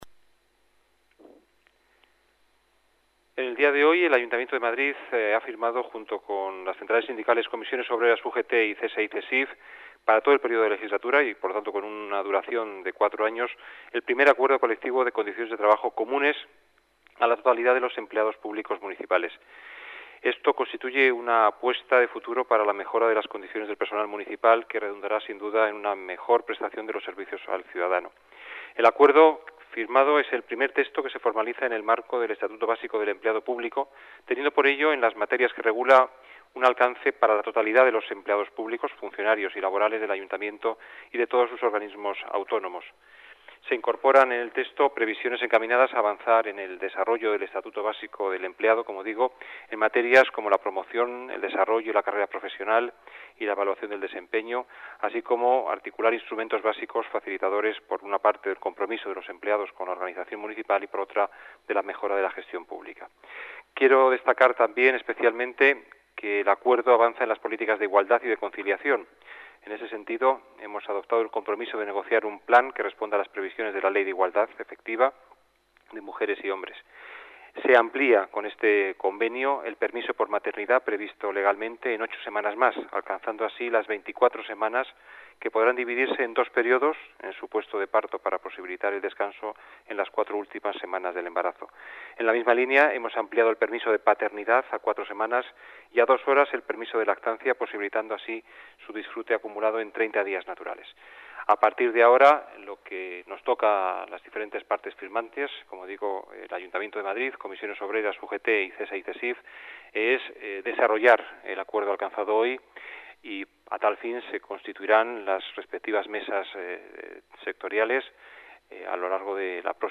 Nueva ventana:Declaraciones del concejal de Hacienda, Juan Bravo